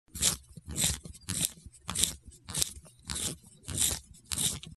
Звуки рисования
Звук заточки карандаша вручную